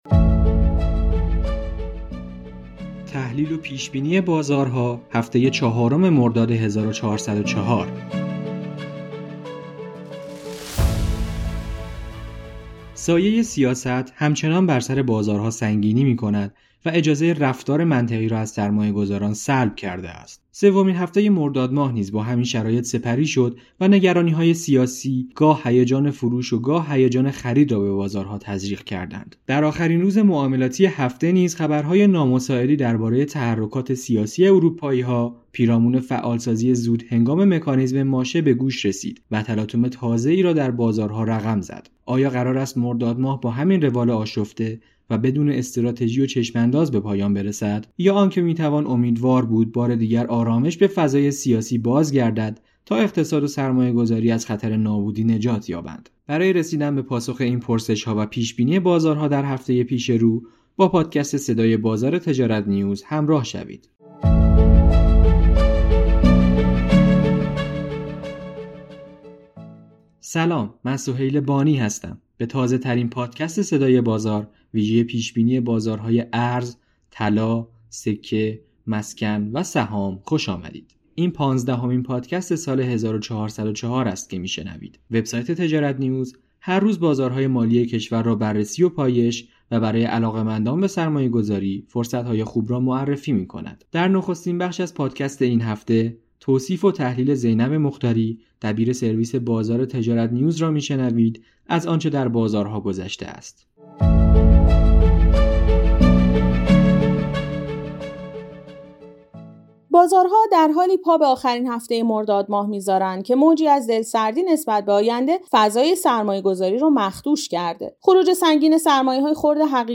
به گزارش تجارت نیوز، به تازه‌ترین پادکست صدای بازار ویژه پیش بینی بازارهای ارز، طلا، سکه، مسکن و سهام خوش آمدید.